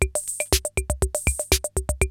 CR-68 LOOPS4 5.wav